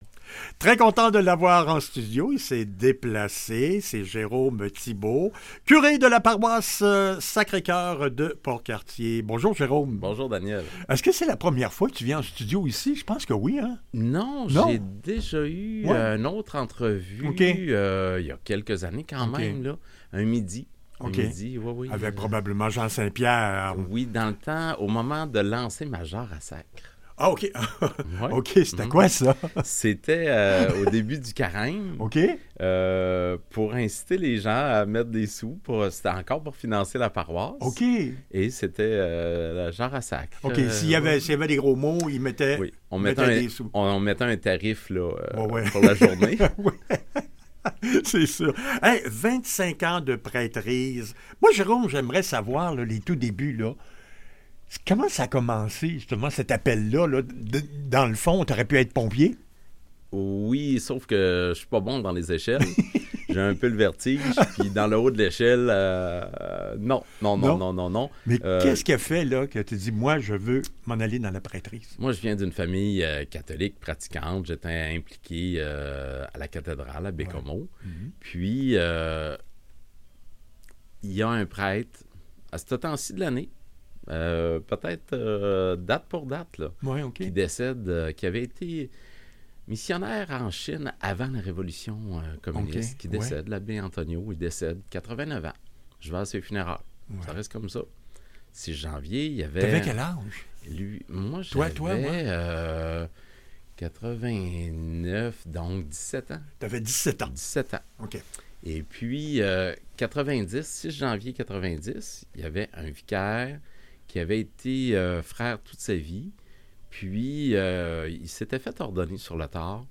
Entrevue 94,1Fm | Paroisse Sacré-Coeur de Port-Cartier